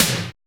ENRGYTOM LO.wav